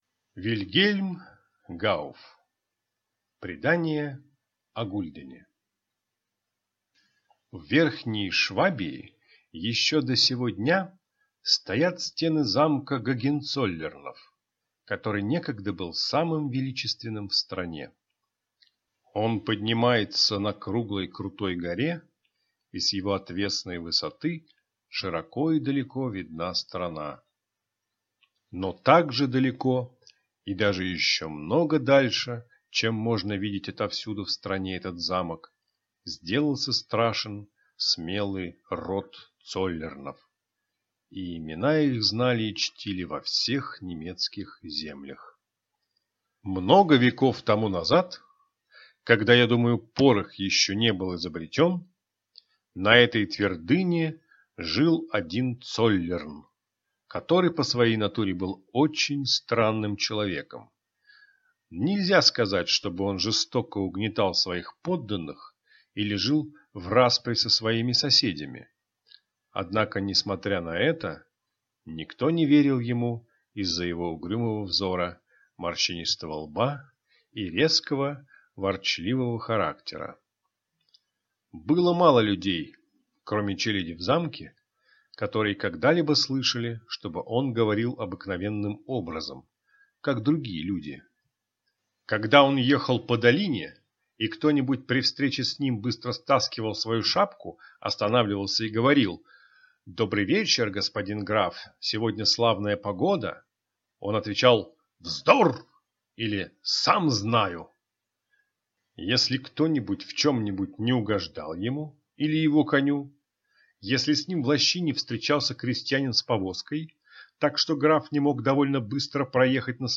Аудиокнига Предание о гульдене | Библиотека аудиокниг